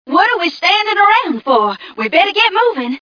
1 channel
mission_voice_ghca058.mp3